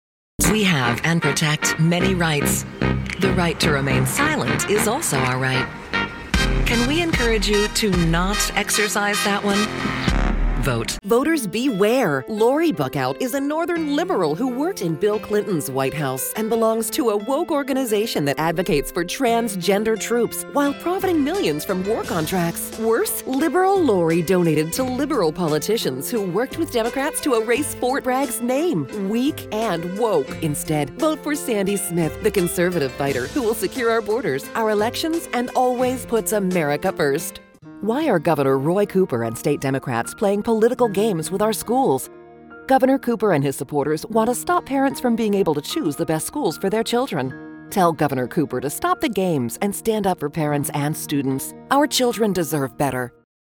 Female Republican Voices
Variety of great voice actors with pro home studios and Source Connect.